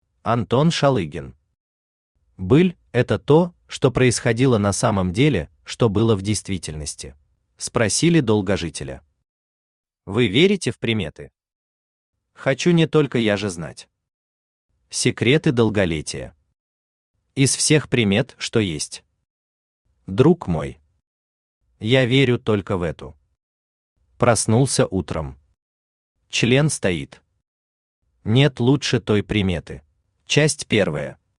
Аудиокнига Быль – это то, что происходило на самом деле, что было в действительности | Библиотека аудиокниг
Aудиокнига Быль – это то, что происходило на самом деле, что было в действительности Автор Антон Шалыгин Читает аудиокнигу Авточтец ЛитРес.